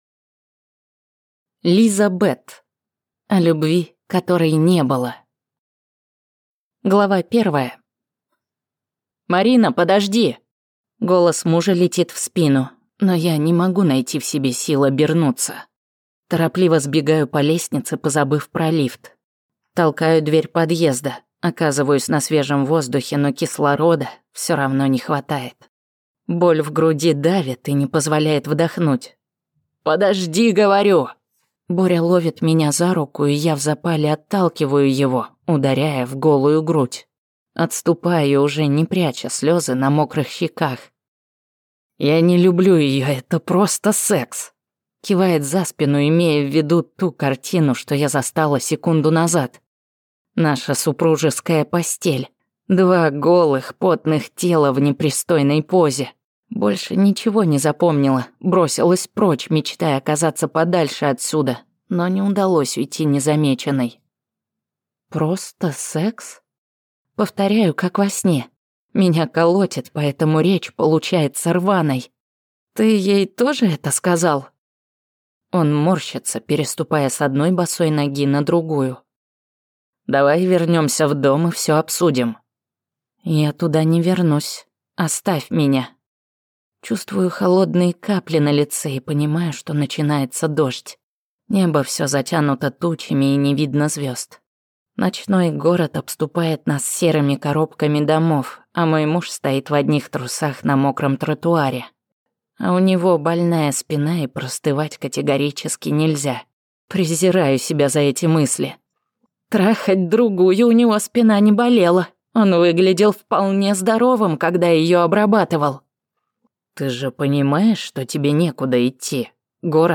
Колыбель цивилизаций II. Книга 8. Стиратель (слушать аудиокнигу бесплатно) - автор Юрий Тарарев